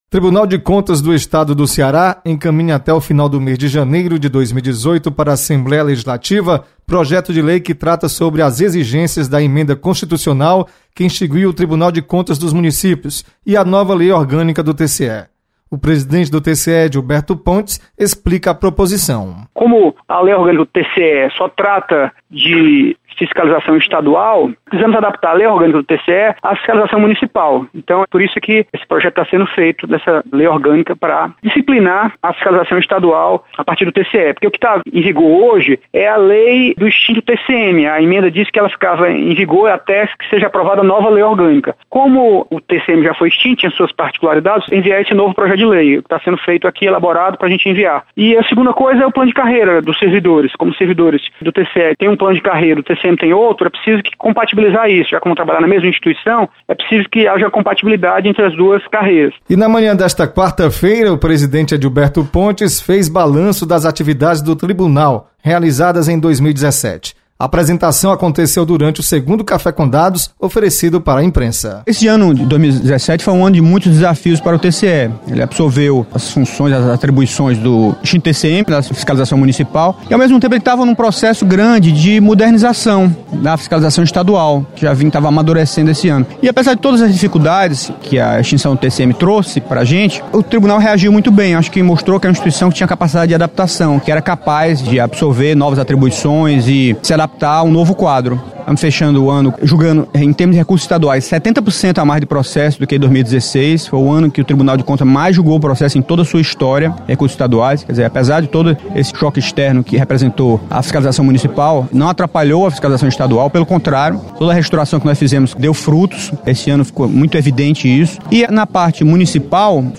TCE anuncia mensagem que enviará ao Parlamento Estadual. Repórter